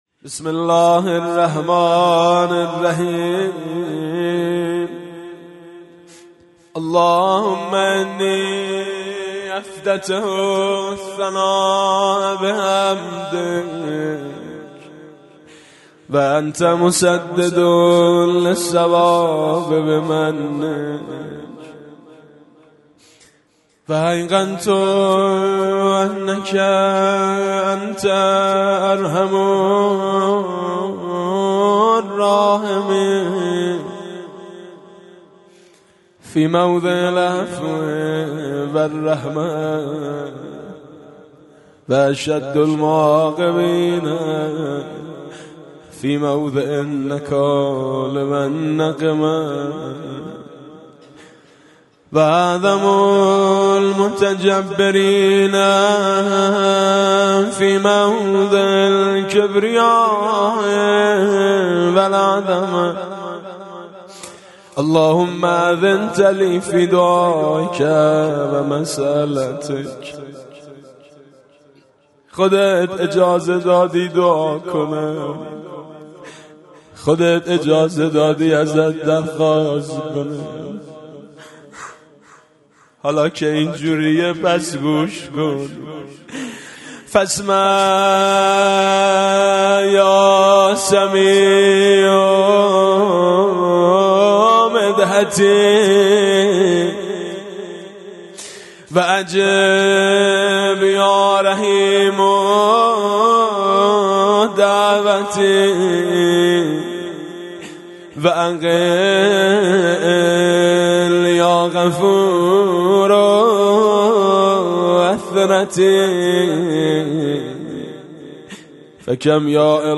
قرائت دعای افتتاح- حاج میثم مطیعی